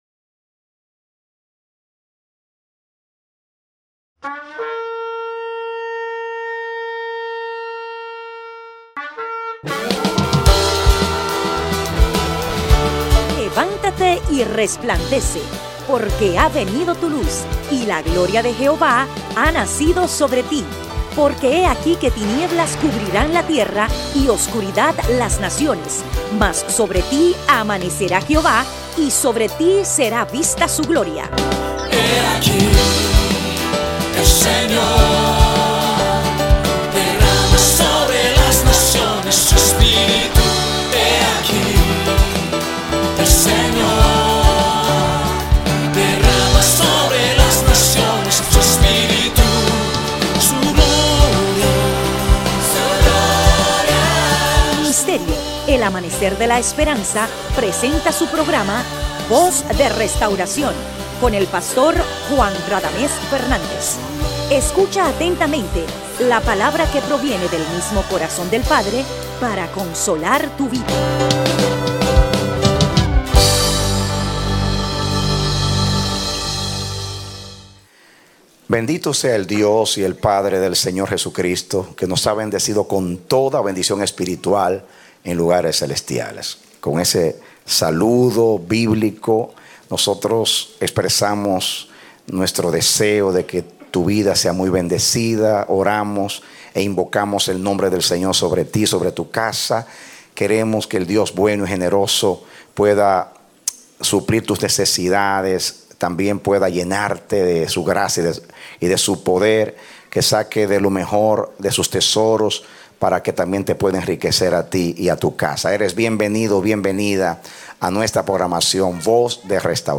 A mensaje from the serie "Mensajes." Predicado Octubre 16, 2014